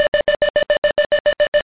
StallIntermitent.mp3